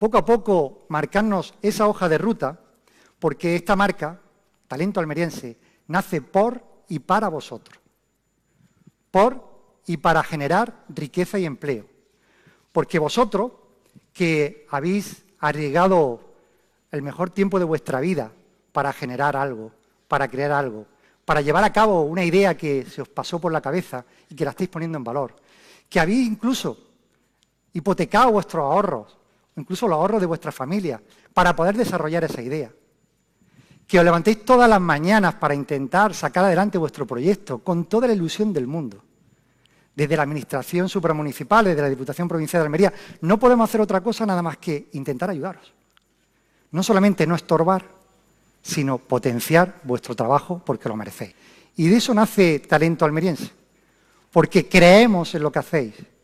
19-09_talento_almeriense_presidente_por_y_para_vosotros.mp3